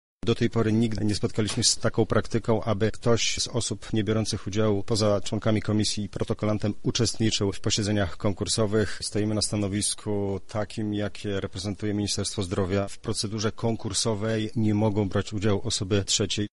— mówi poseł Marta Wcisło.